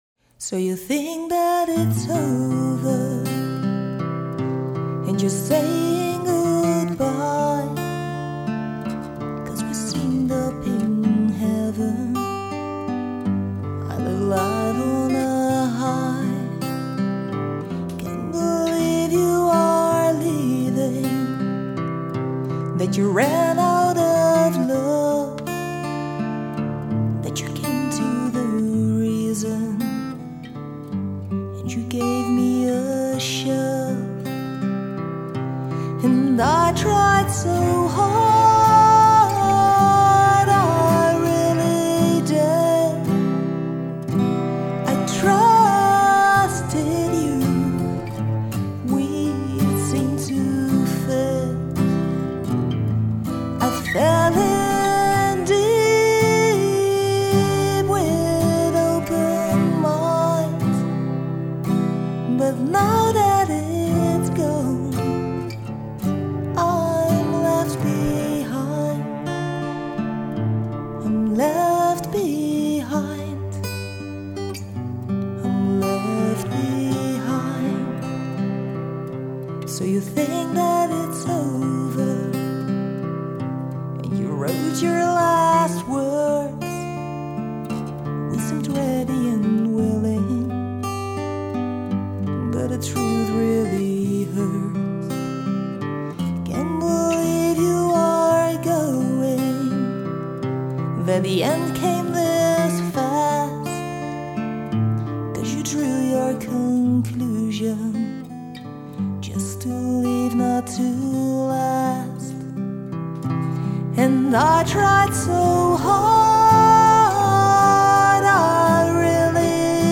gitaar